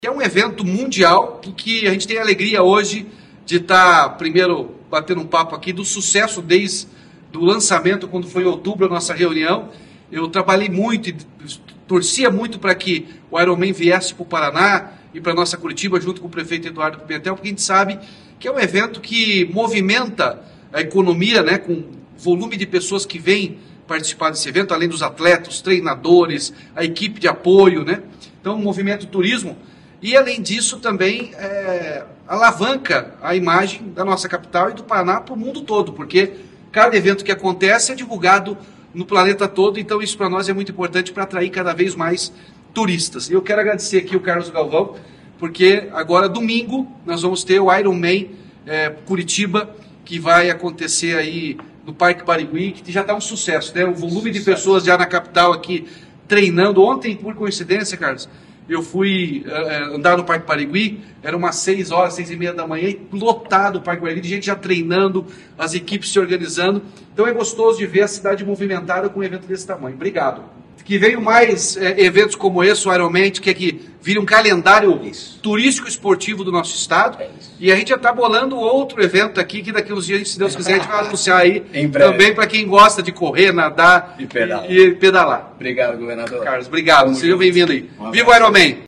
Sonora do governador Ratinho Junior sobre a etapa “70.3 Curitiba” do IRONMAN BRASIL